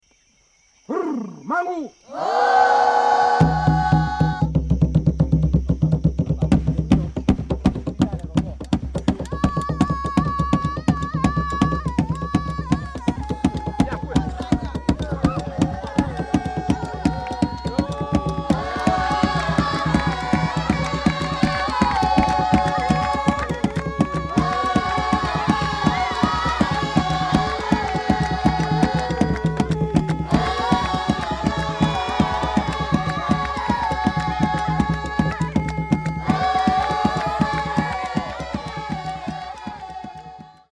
"Canto rituale dei Baka" tribù pigmea dell'Africa centrale
Danze e canti africani
1 Buma (Baka, Camerun Sudorientale), tratto da: Rosenberg Colorni M., Il canto della foresta: musiche e canti rituali delle tribu della foresta pluviale, Como: Red!